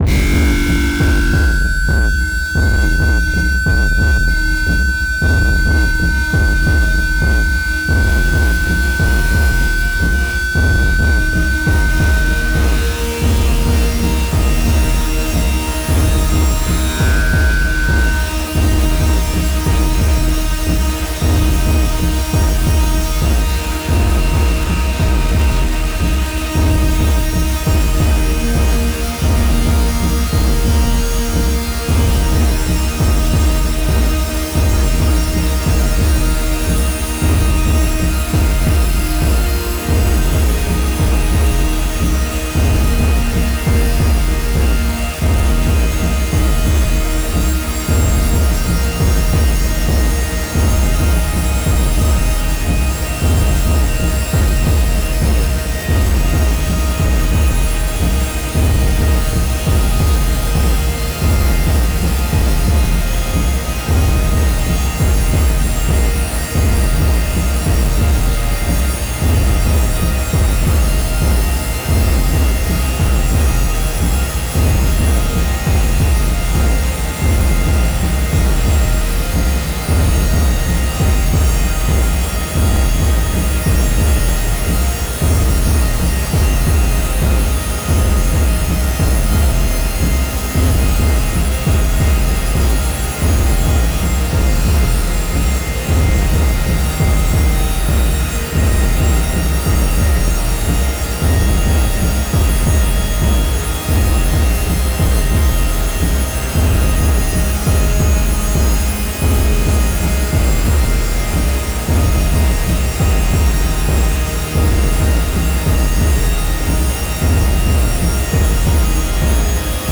大地を轟かす太く重い狼の”鼓動”。
天と地を結ぶ霊獣の為の、美しく力強い祝祭曲。
たいへんデリケートで複雑なパンニングと、スペクトル分布を特徴とするアルバムです。
現代音楽、先端的テクノ、実験音楽をお好きな方々にはもちろん、ギター愛好家の方々にもお薦めのアルバムです。